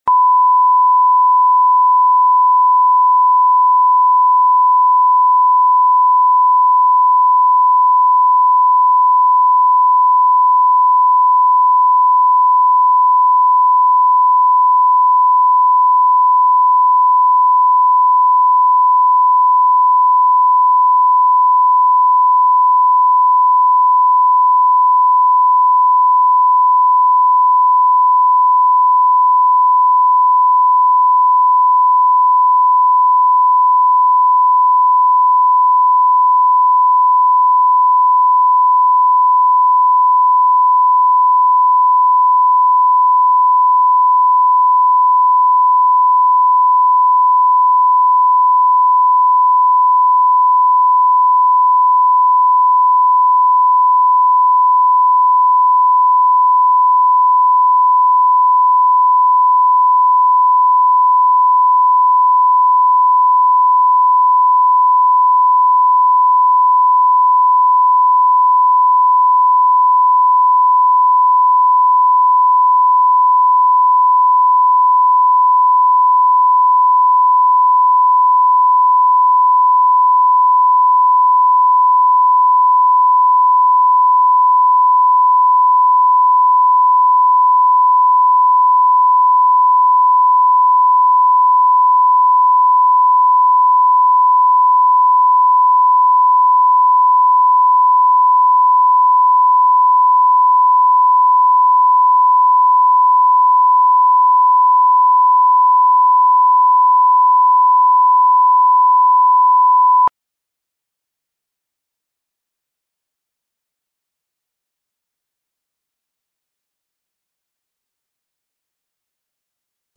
Аудиокнига Львиное сердце | Библиотека аудиокниг